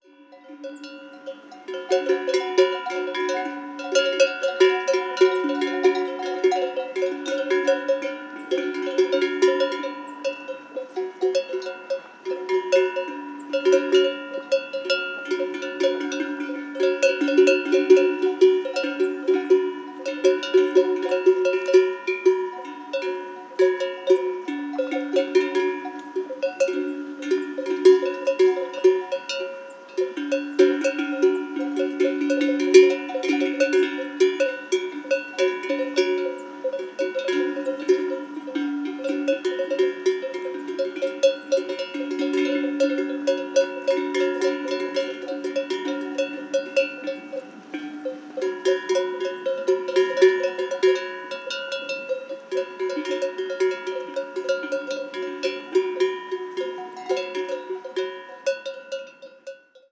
Ambiente de vacas en el prado
Sonidos: Animales Sonidos: Rural